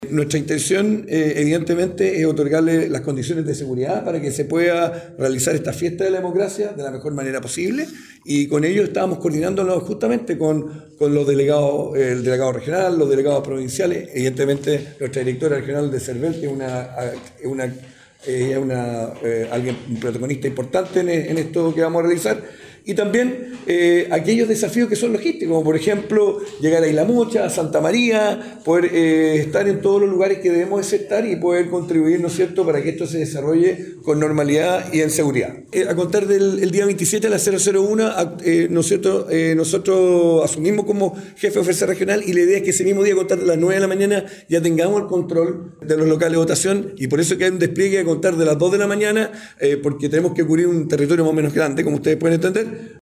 En tanto, el jefe de la Fuerza Regional, contraalmirante Arturo Oxley, adelantó que “vamos a desplegar más de dos mil 600 hombres y mujeres, tanto de Fuerzas Armadas como de Carabineros, para poder cubrir los locales de votación y también los 23 colegios escrutadores”.